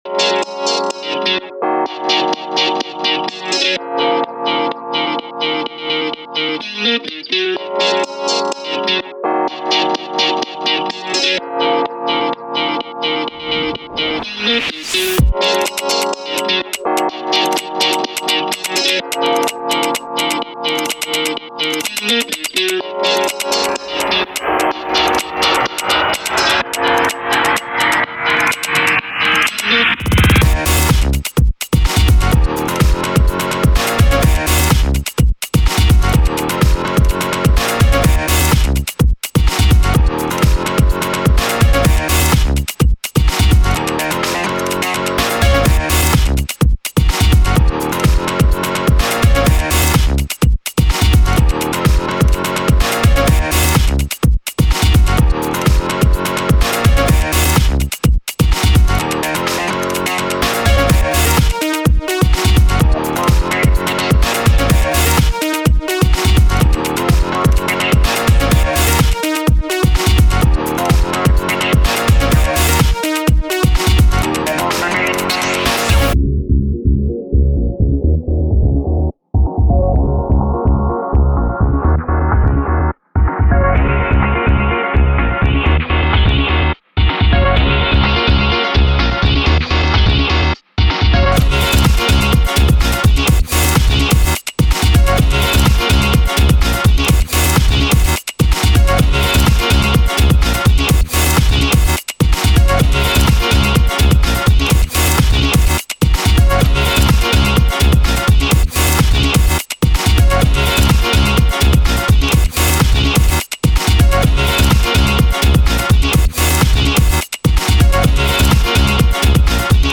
Nu-disco/Indie Dance